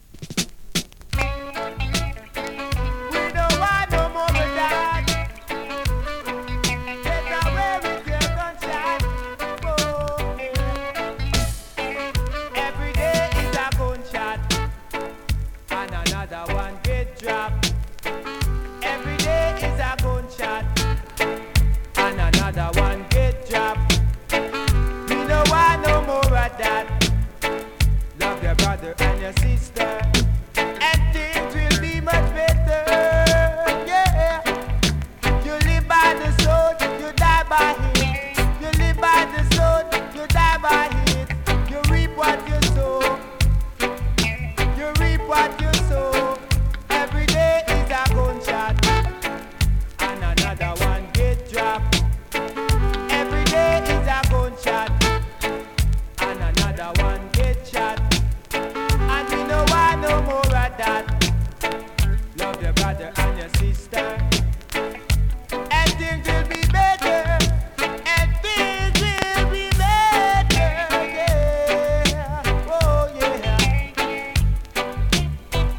ホーム > 2023 NEW IN!! DANCEHALL!!
スリキズ、ノイズ比較的少なめで